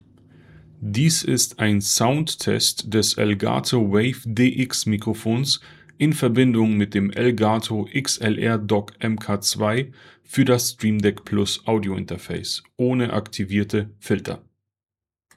Soundtest
Softwareseitig ist die Lautstärke auf 60 % eingestellt und die Aufnahmedistanz beträgt etwa 20 Zentimeter.
Test 1: Elgato XLR DOCK MK.2 ohne Filter